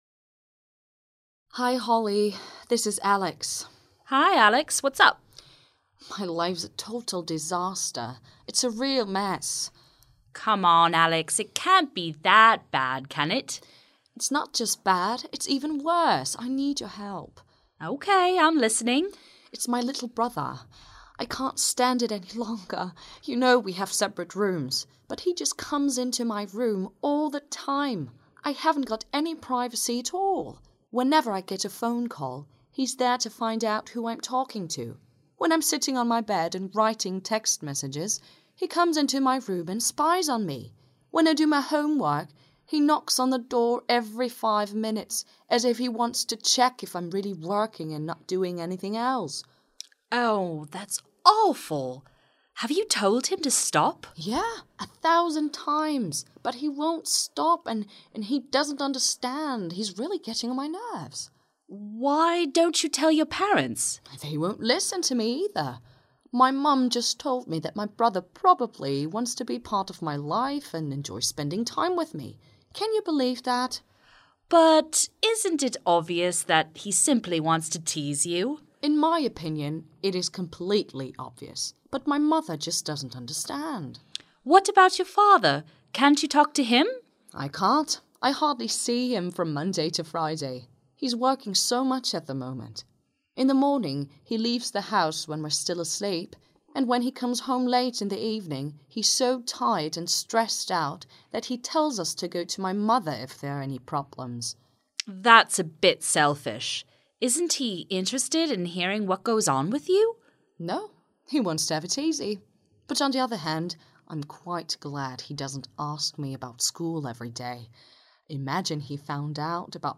A phone call between friends
Listening/Viewing • • Listening/viewing practice • A phone call between friends
phone_call_school_problems.mp3